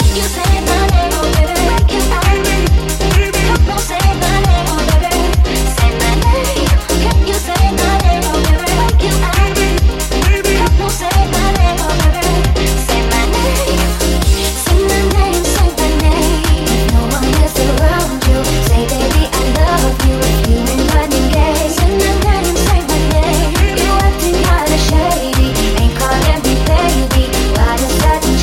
Genere: pop,house, deep, club, remix